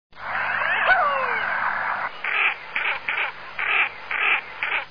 Głowienka - Aythya ferina
głosy